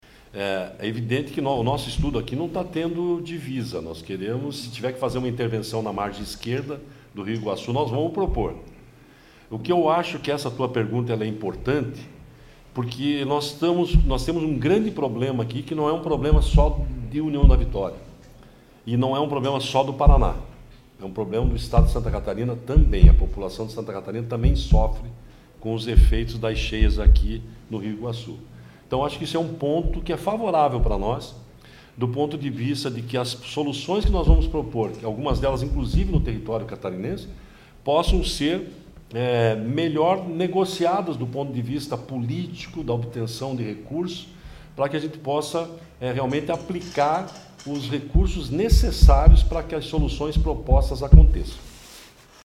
Durante a coletiva, foi destacado que a geografia de União da Vitória — localizada em uma área de confluência e cercada por morros — a torna naturalmente mais suscetível a alagamentos severos.